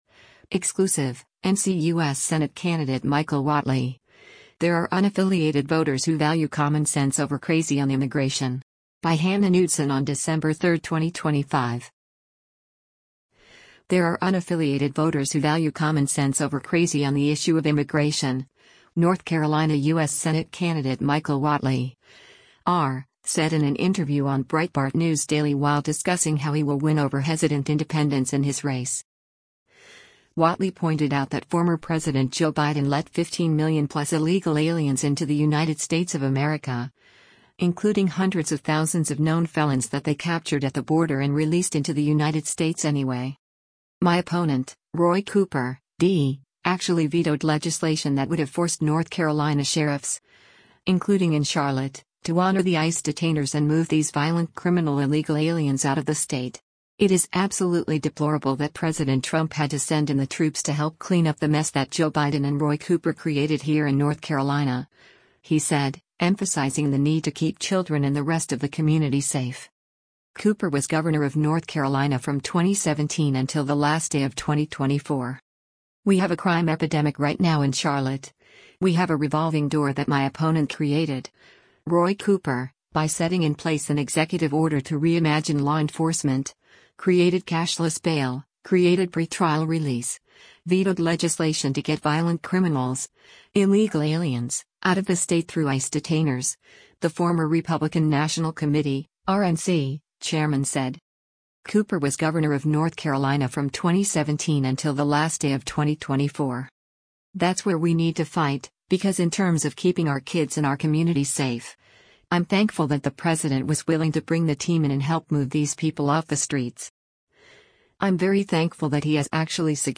There are unaffiliated voters who value common sense over crazy on the issue of immigration, North Carolina U.S. Senate candidate Michael Whatley (R) said in an interview on Breitbart News Daily while discussing how he will win over hesitant independents in his race.
Breitbart News Daily airs on SiriusXM Patriot 125 from 6:00 a.m. to 9:00 a.m. Eastern.